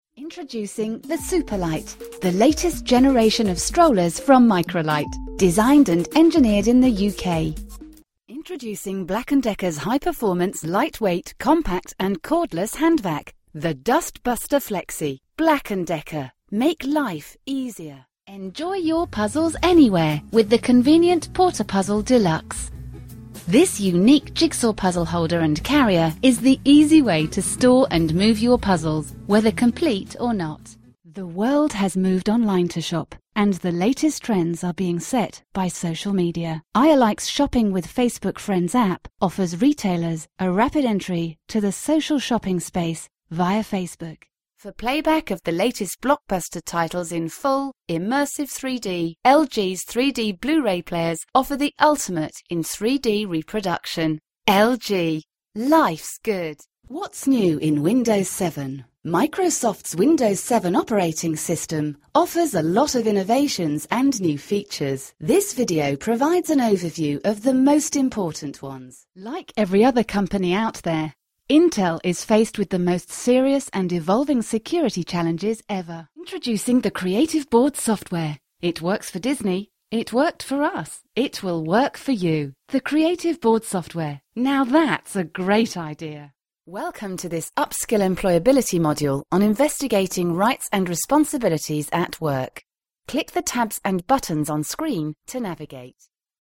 A selection of corporate narrations